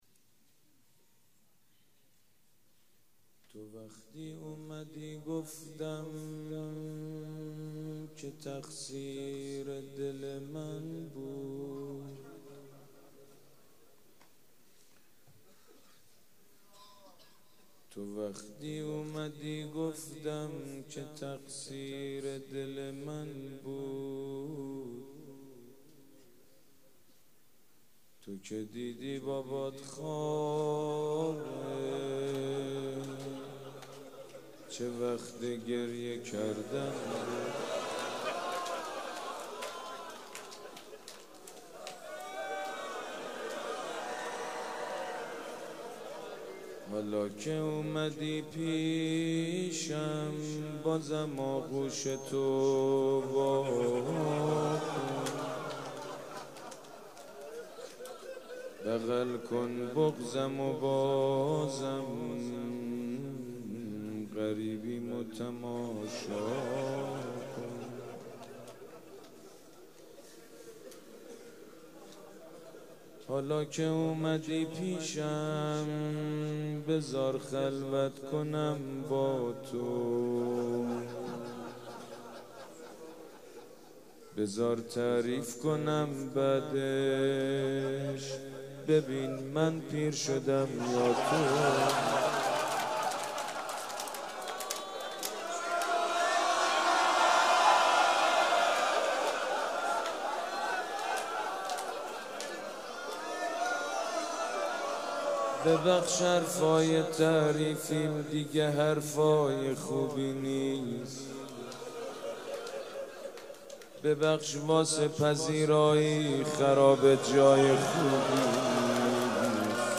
روضه شب سوم مراسم عزاداری صفر
روضه
مداح
مراسم عزاداری شب سوم